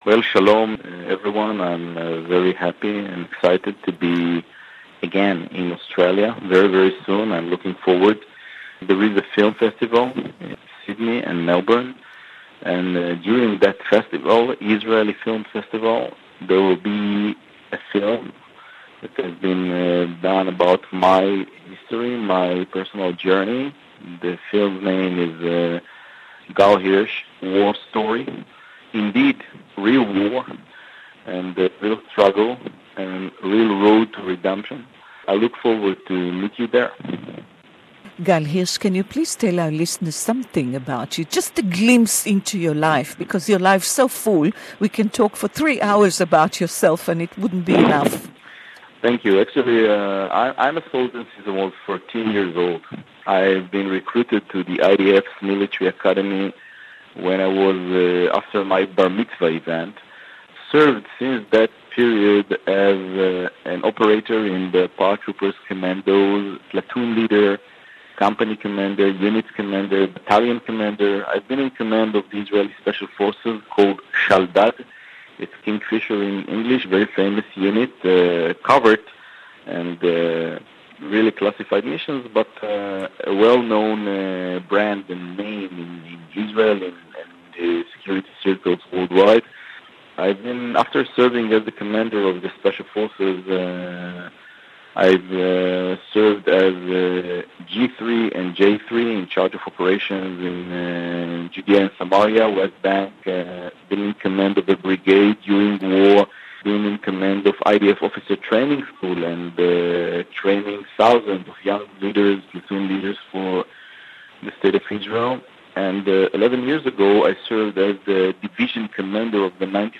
Brigadier General Gal Hirsch, is in Australia as the guest of the Israeli film Festival. War Story, the documentary is an intimate glimpse into his life and career, the legendary Israeli hero. Gal told our listeners that it is a tragedy that he was forced to leave the IDF (English Interview)